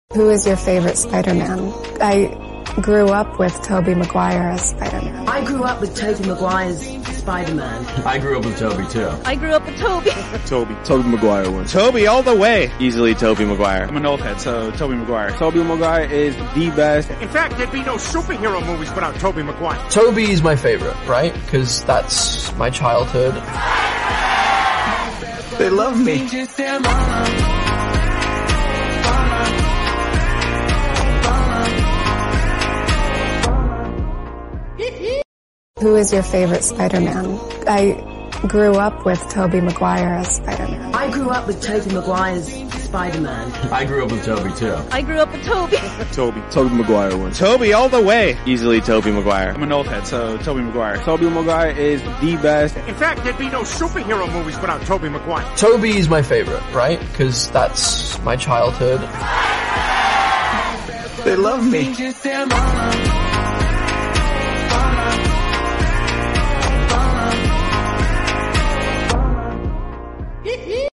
Slowed & Reverb